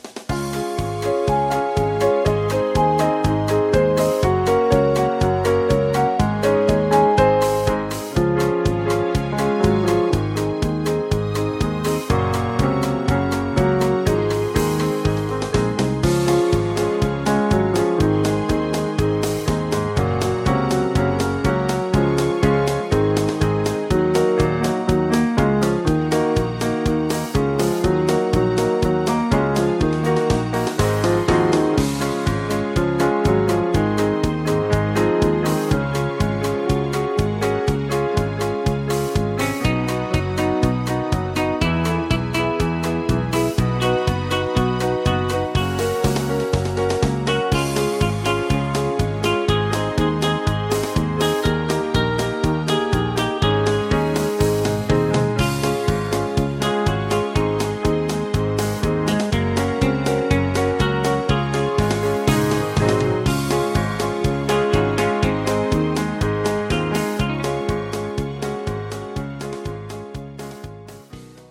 Instrumental with BGV's
Singing Calls